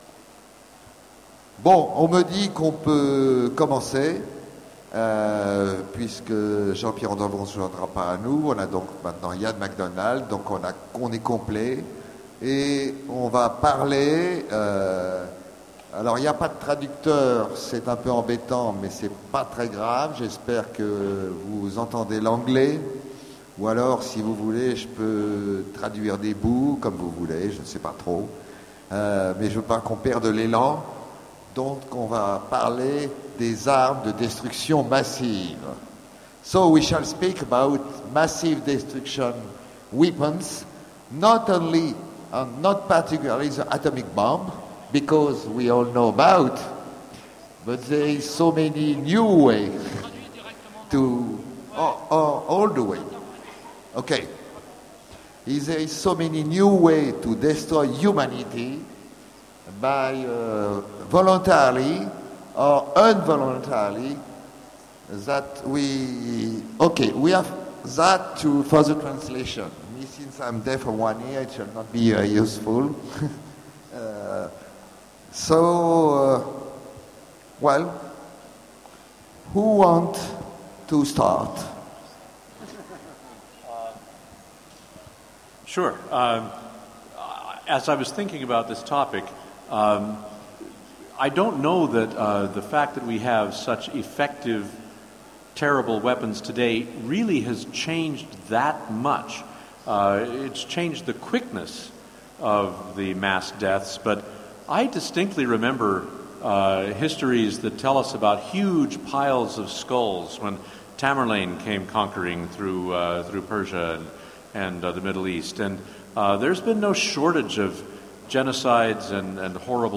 Utopiales 13 : Conférence L'homme, une arme de destruction massive